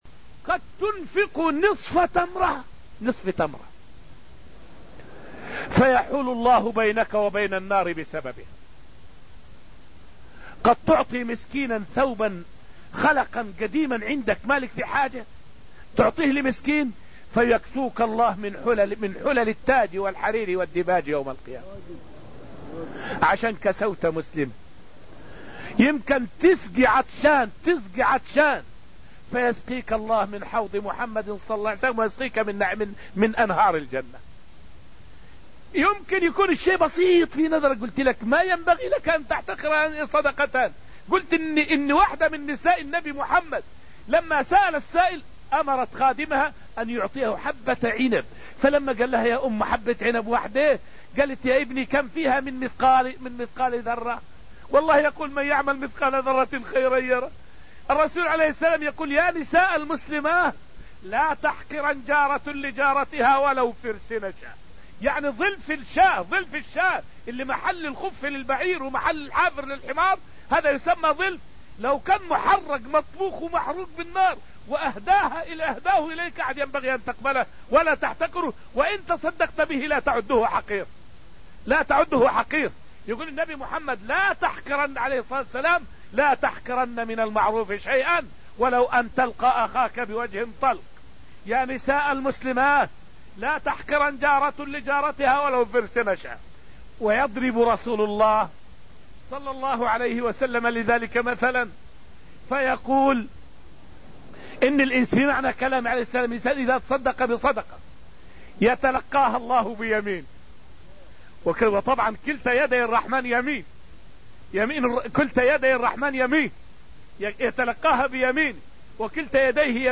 فائدة من الدرس الثامن من دروس تفسير سورة الحديد والتي ألقيت في المسجد النبوي الشريف حول الحث على الإنفاق ولو بالقليل.